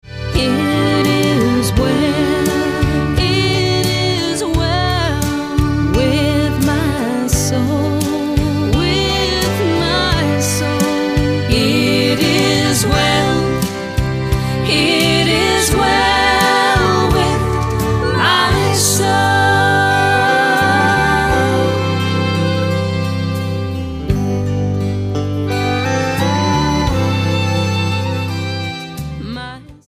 STYLE: Country